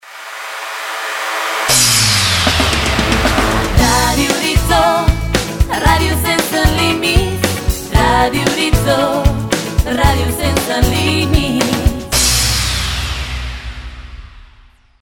Sintonia genèrica de la ràdio amb identificació cantada